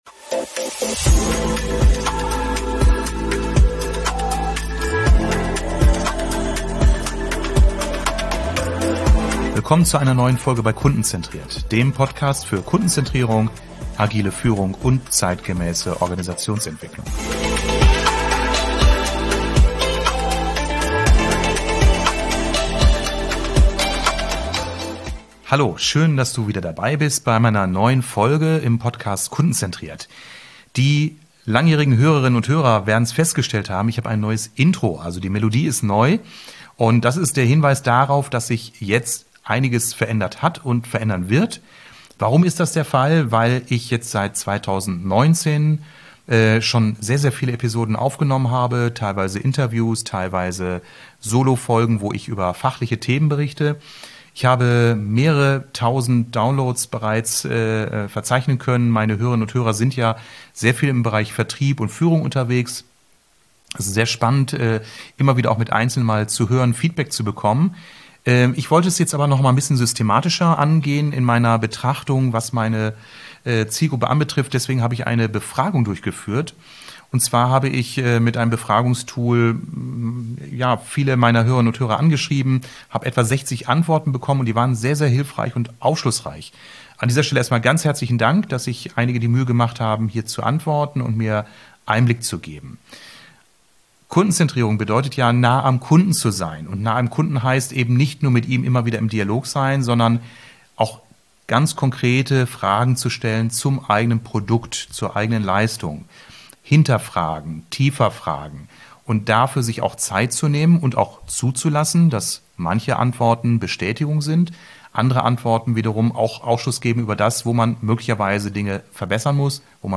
Interviews, Expertenmeinungen und Tipps rund um den kundenzentrierten Vertrieb, agile Führungsprinzipien und innovative Strategien im Vertrieb.